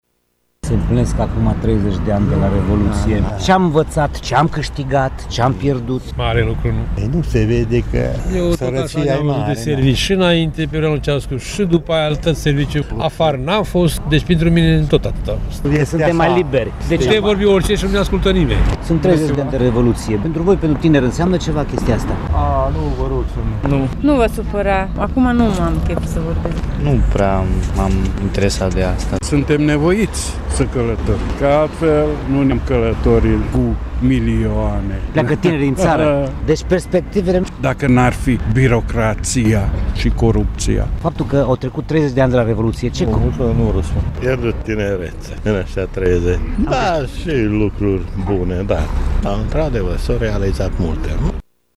Acestea sunt câteva dintre opiniile celor chestionați despre semnificația momentului din Decembrie 1989 și cei 30 de ani care au trecut de atunci, în România.